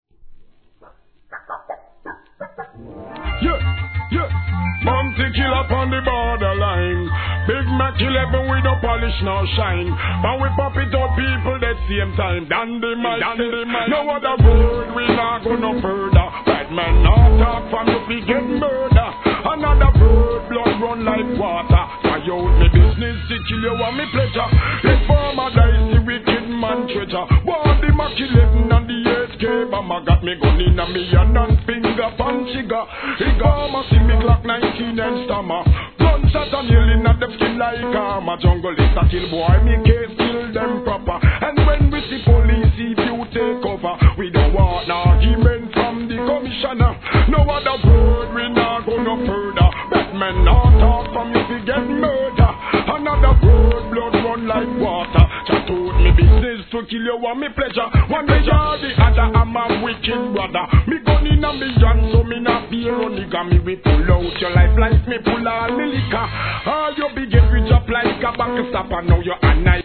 REGGAE
ラガHIP HOP No. タイトル アーティスト 試聴 1.